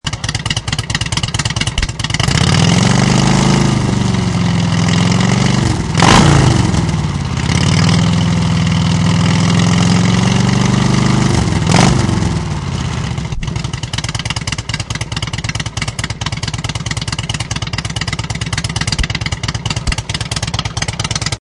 Download Motorcycle sound effect for free.
Motorcycle